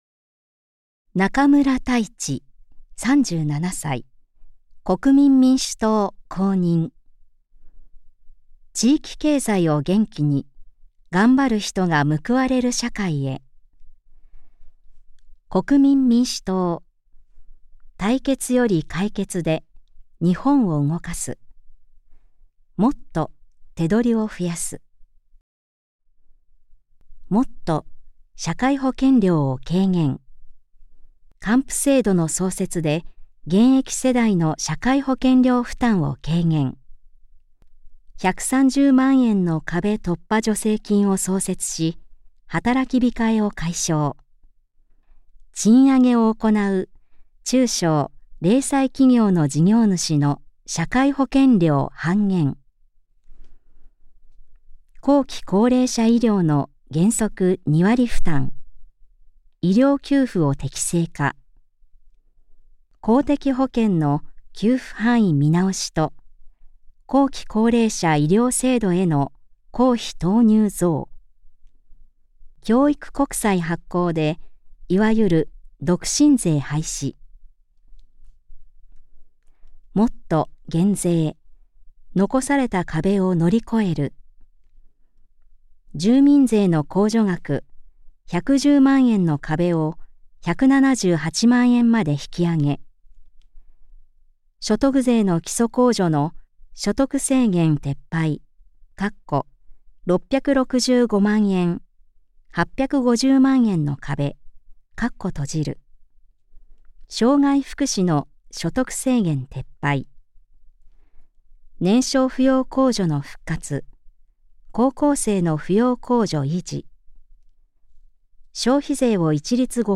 衆議院議員総選挙　候補者・名簿届出政党等情報（選挙公報）（音声読み上げ用）